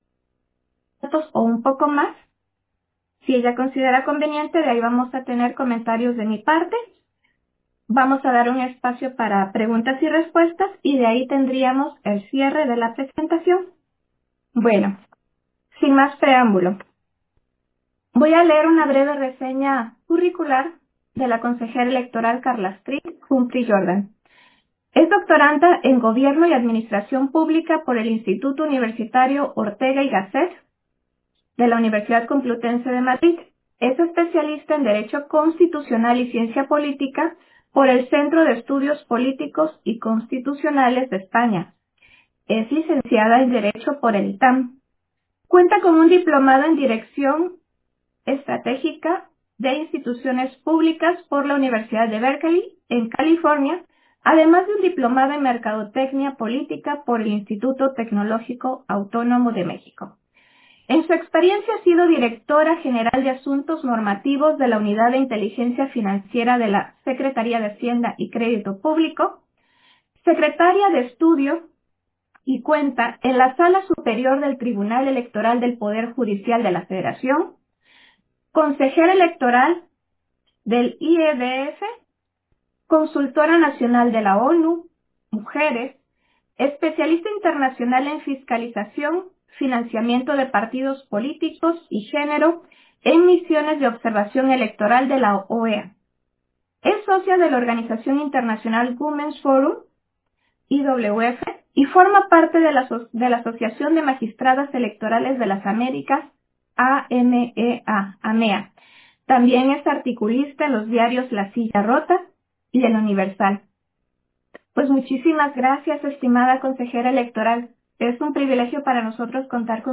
Audio de la conferencia de análisis especializado 2: Reforma electoral integral, en el marco del XXXVI Congreso Internacional de Estudios Electorales